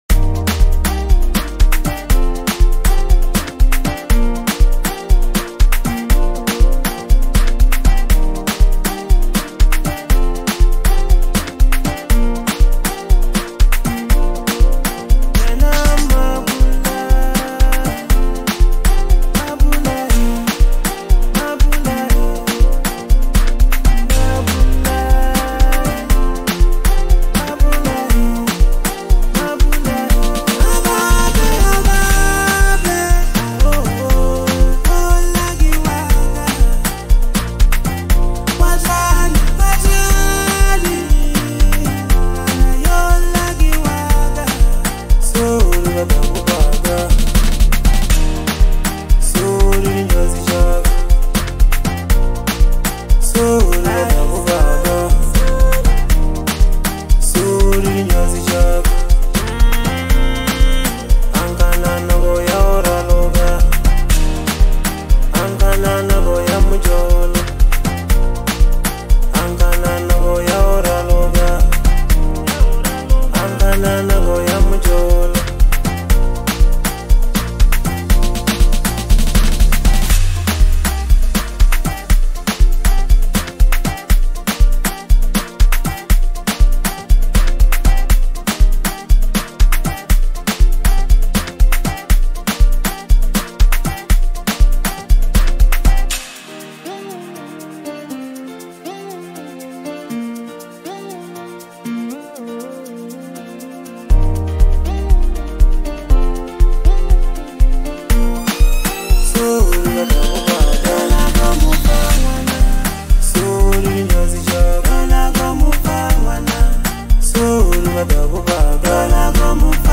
heartfelt and soulful track
emotional yet uplifting song